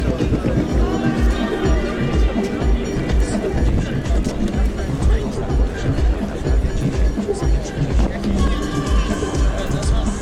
w Pop / Soul